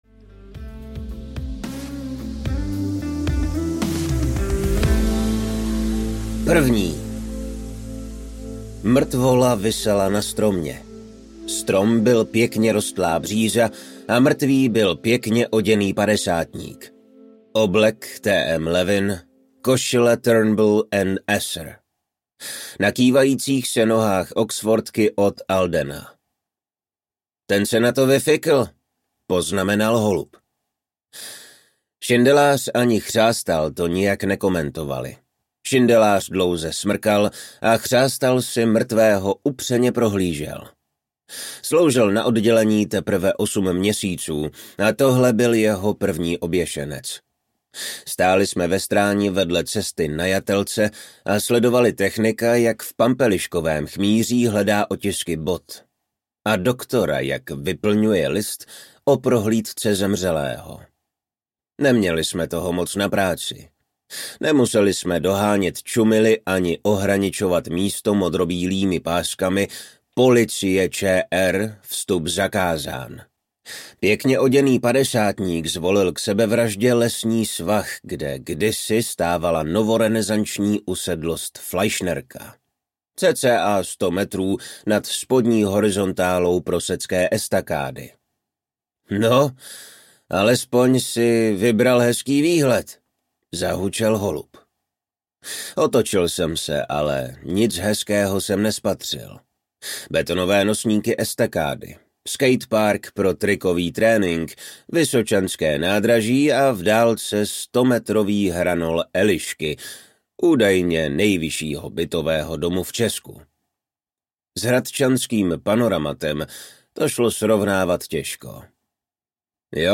Křížová palba audiokniha
Ukázka z knihy